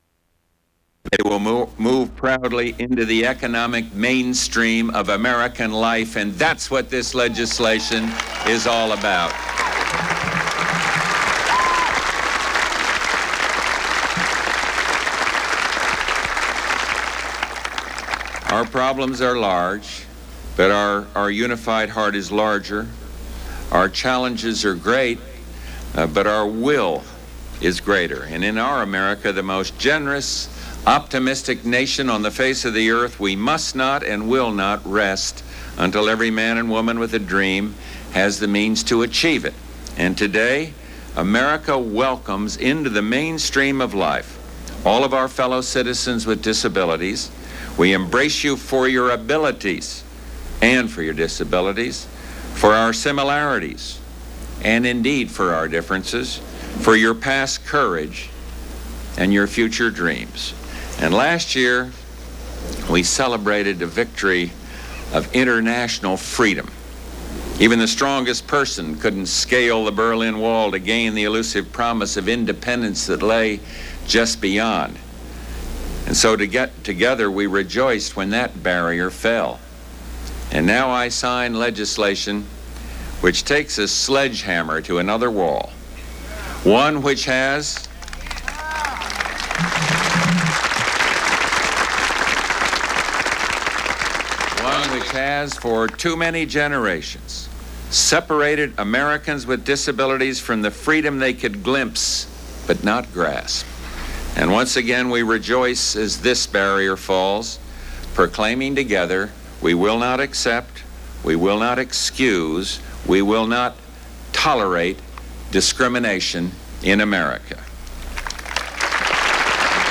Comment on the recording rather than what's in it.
Broadcast on CNN, July 26, 1990.